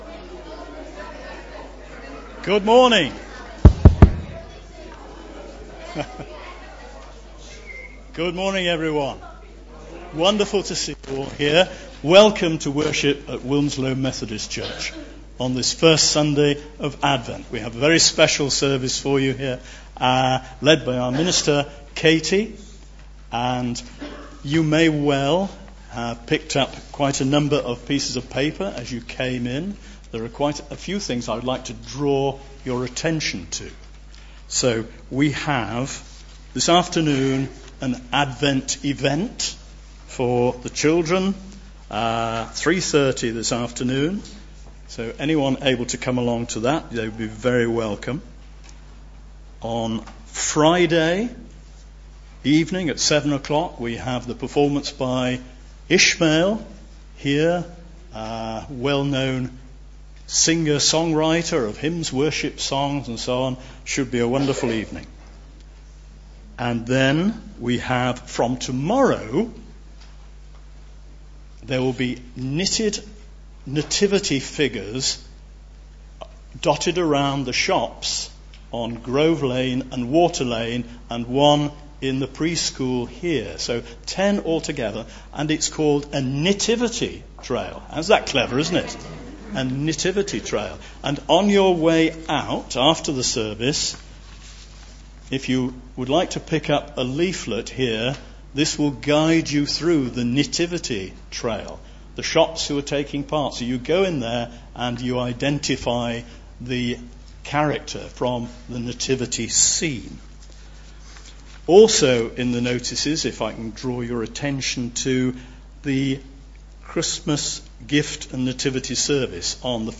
2019-12-01 Advent Parade service
Genre: Speech.